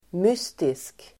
Uttal: [m'ys:tisk]